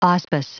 Prononciation du mot auspice en anglais (fichier audio)
Prononciation du mot : auspice